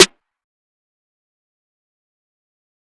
Snares
Sn (FlexZone).wav